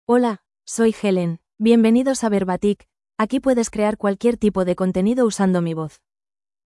FemaleSpanish (Spain)
Helen is a female AI voice for Spanish (Spain).
Voice sample
Female
Helen delivers clear pronunciation with authentic Spain Spanish intonation, making your content sound professionally produced.